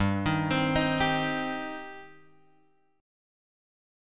G Suspended 4th Sharp 5th